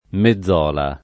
[ me zz0 la ]